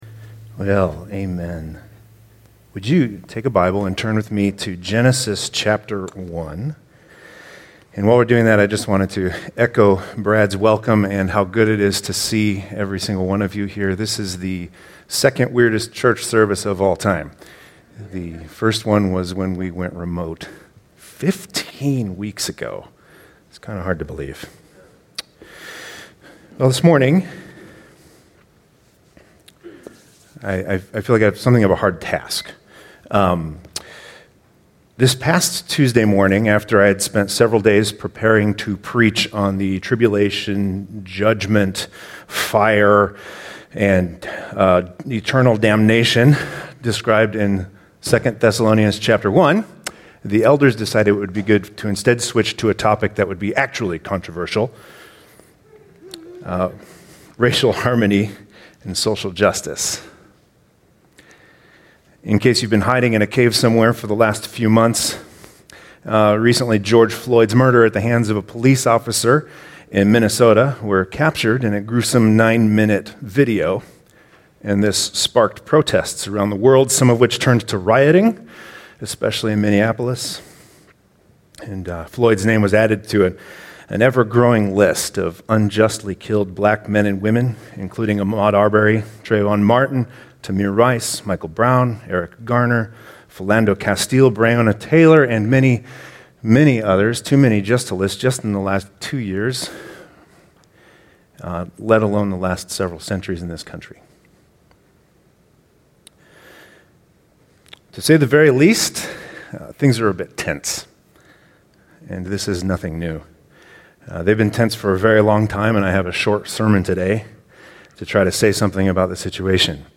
And I have a short sermon today to say something about the situation.